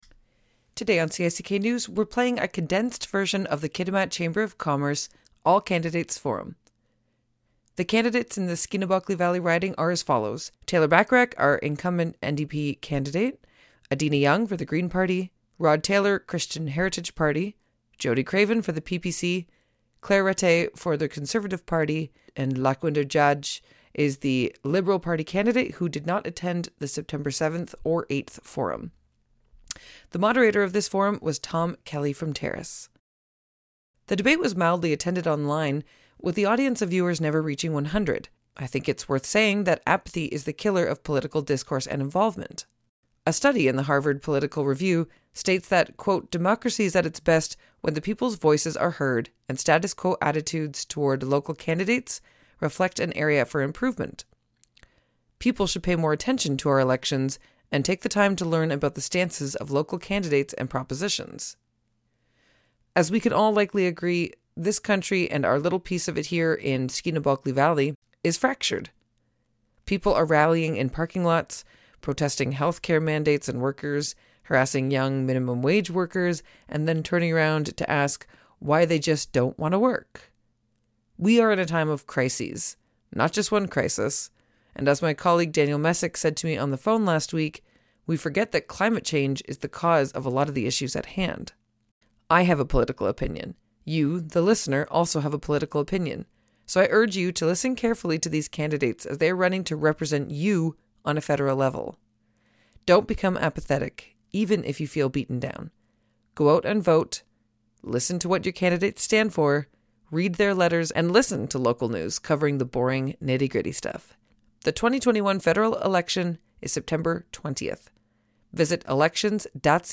All Candidates Debate addresses housing, addictions, and truth and reconciliation